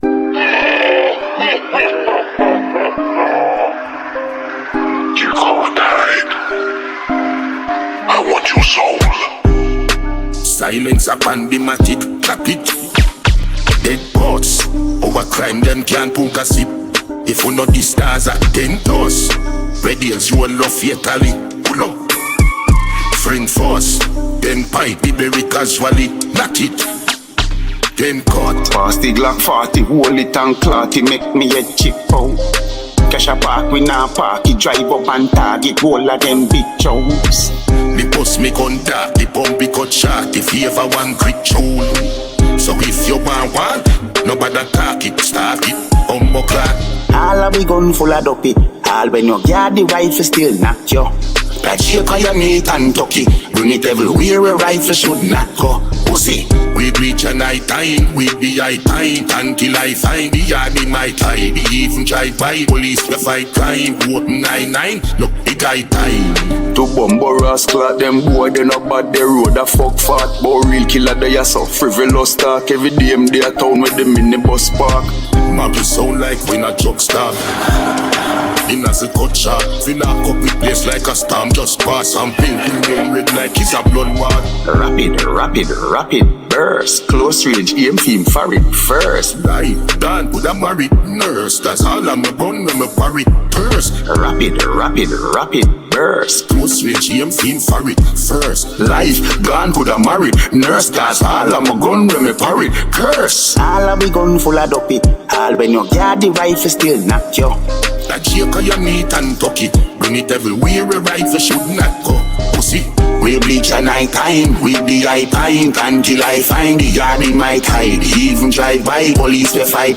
blends Afrobeat with Unique sounds
With its upbeat tempo and catchy sounds